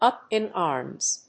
アクセントúp in árms